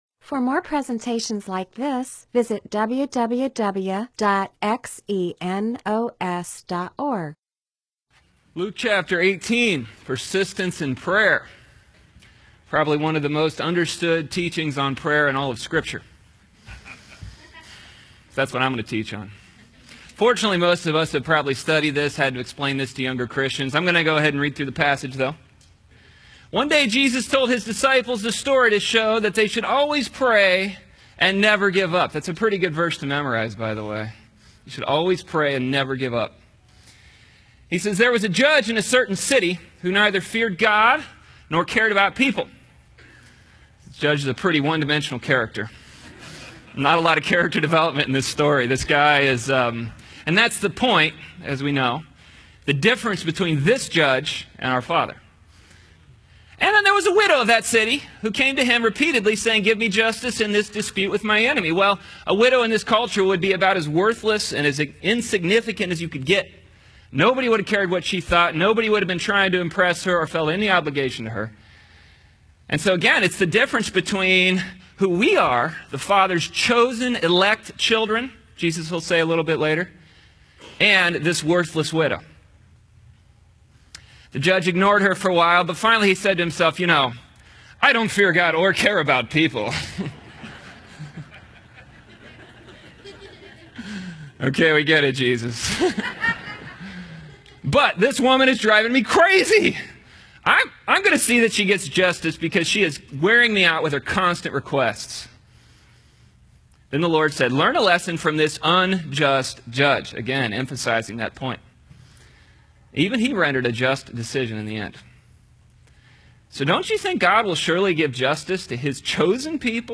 MP4/M4A audio recording of a Bible teaching/sermon/presentation about Luke 18:1-8.